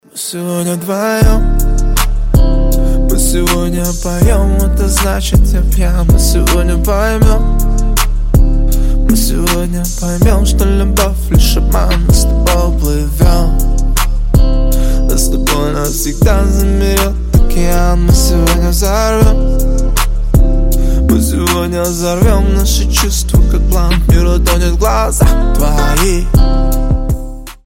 мужской вокал
спокойные
романтичные
лиричные